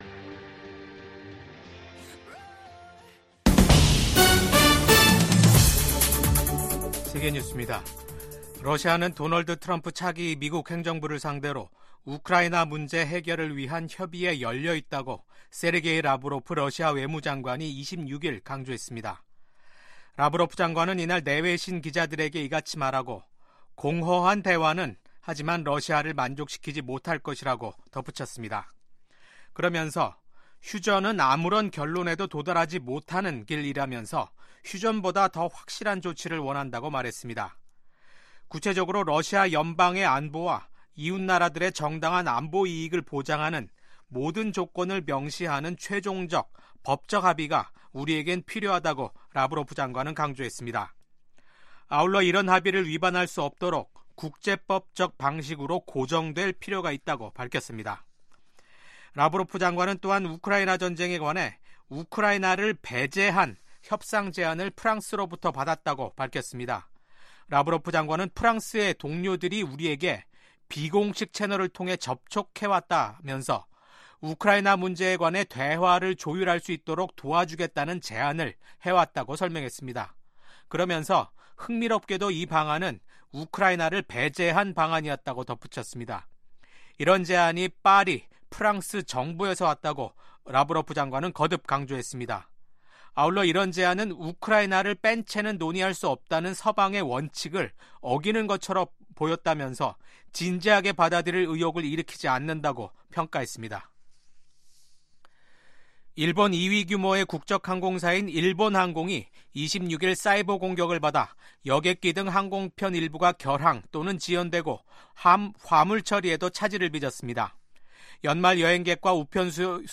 VOA 한국어 아침 뉴스 프로그램 '워싱턴 뉴스 광장'입니다. 비상계엄 사태와 대통령 직무정지로 한국 정정 불안이 지속되는 가운데 한국과 중국 두 나라는 외교장관 간 전화통화를 하는 등 소통을 재개했습니다. 미국 의회 중국위원회가 지난 1년 간의 조사 및 활동을 기술한 연례 보고서를 통해 중국 내 탈북민 문제와 관련된 심각한 인권 침해에 우려를 표명했습니다.